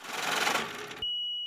[BP] Transition (4).wav